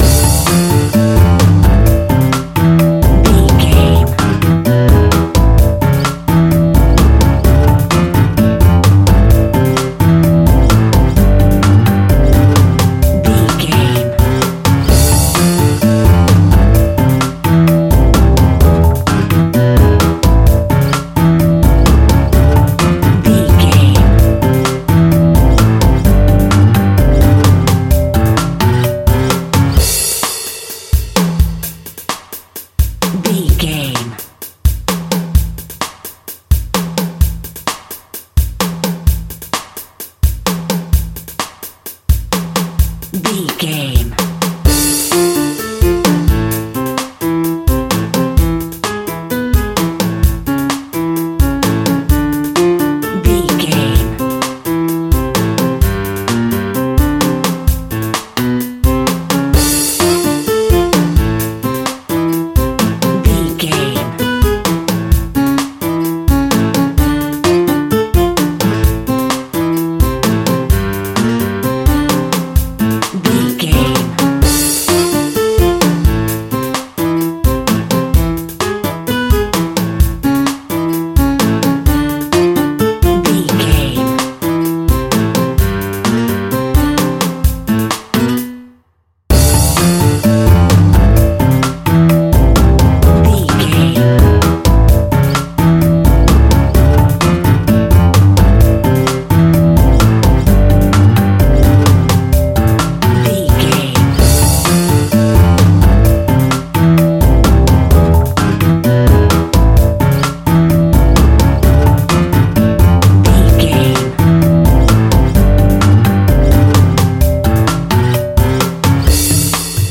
Aeolian/Minor
tropical
rumba
percussion
bongos
steel drum
bass guitar
acoustic guitar